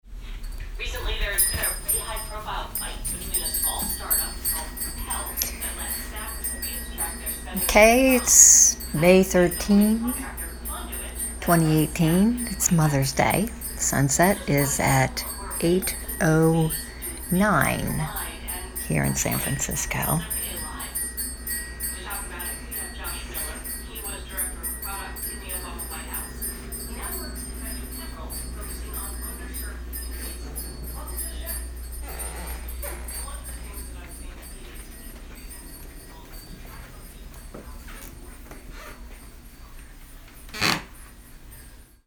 May 13, 2018. Sunset 8:10 PM San Francisco, CA.